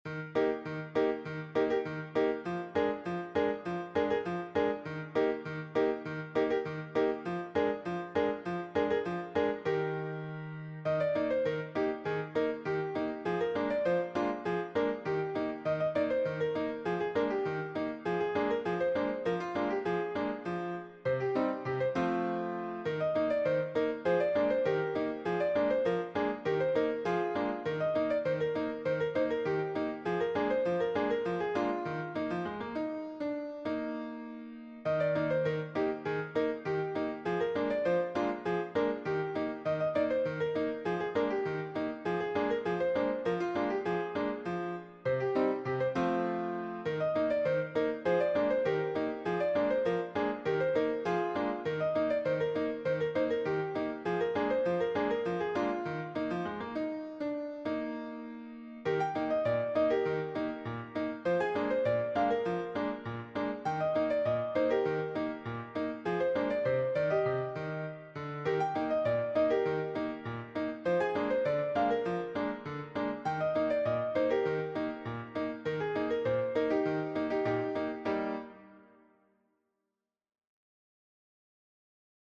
classic ragtime flair
Playful
Modern ragtime
Piano only